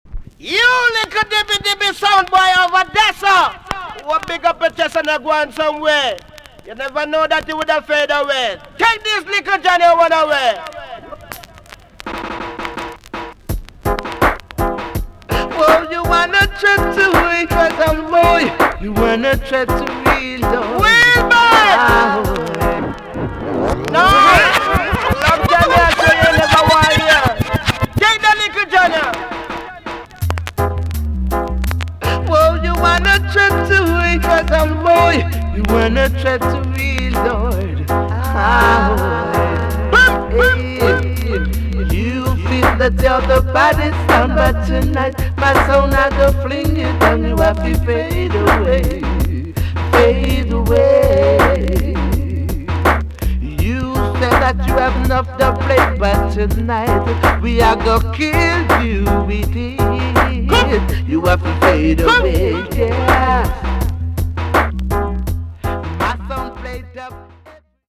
TOP >80'S 90'S DANCEHALL
VG+ 少し軽いチリノイズが入ります。